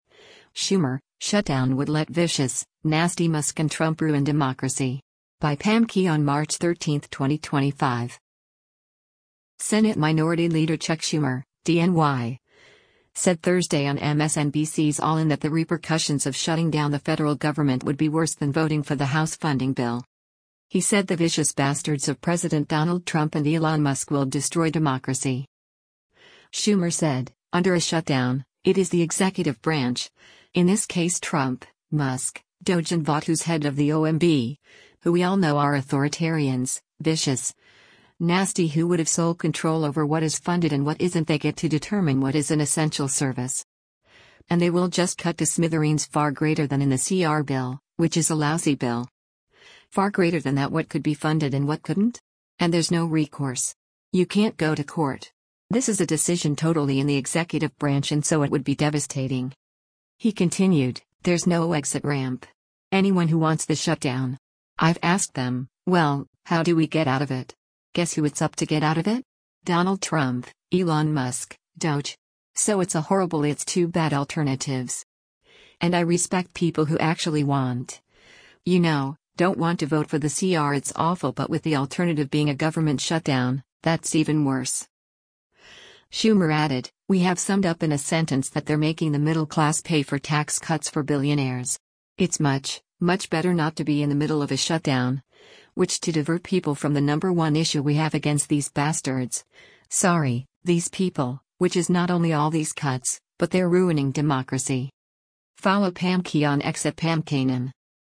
Senate Minority Leader Chuck Schumer (D-NY) said Thursday on MSNBC’s “All In” that the repercussions of shutting down the federal government would be worse than voting for the House funding bill.